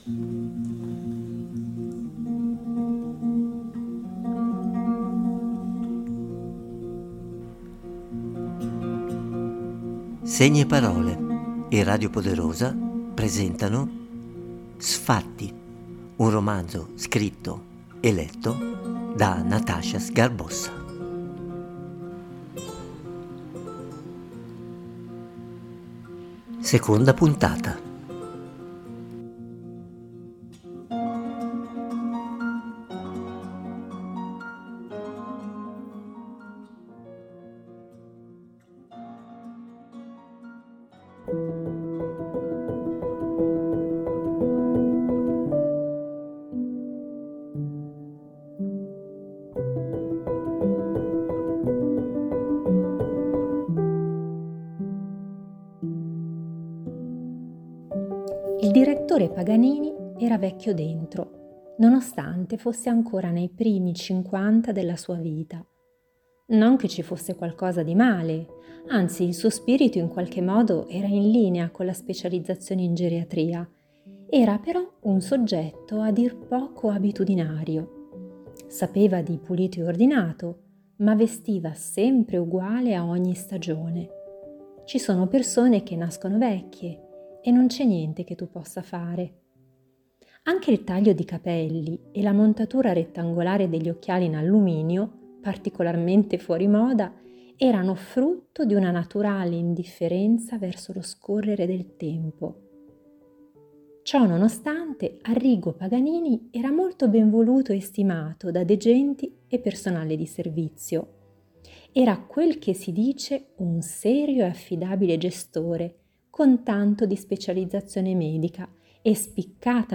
Segni e parole e Radio Poderosa presentano: Sfatti un romanzo scritto e letto